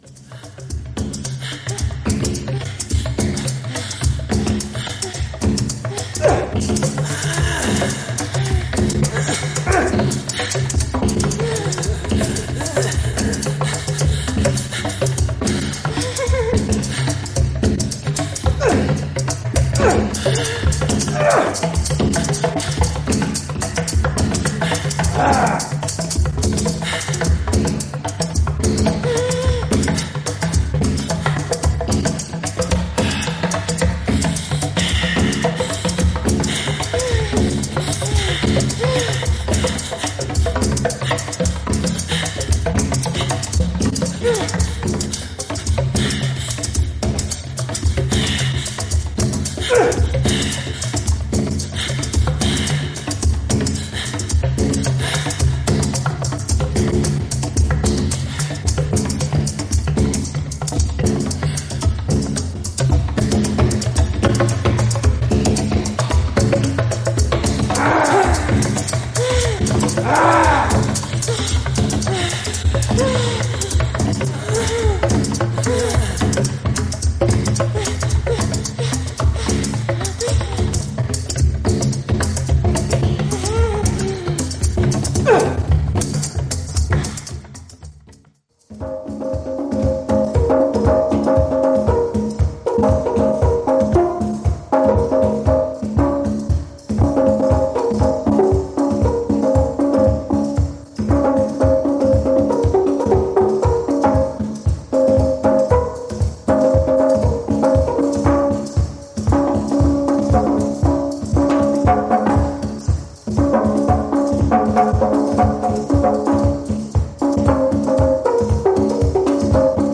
プリミティブで土着的なアフロ・パーカッションを軸に、唸り声や息遣い、動物の鳴き声などが絡み合うオーセンティックな楽曲から
エキゾでモンドなアフロ作品。